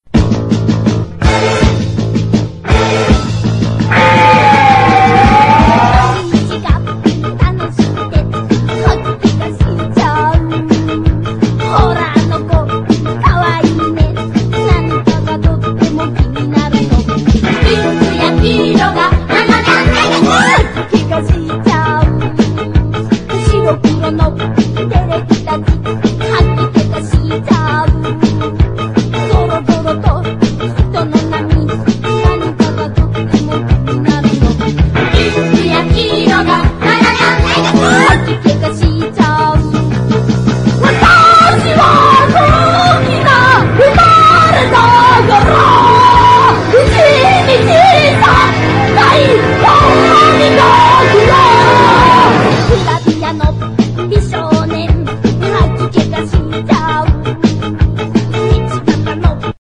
EASY LISTENING
コーラス入りのマヂソン・ツイスト・ラウンジ！
男女混声コーラスがチャーミングに踊る
キャッチーな合唱コーラス・フック＋ヴァイブを転がした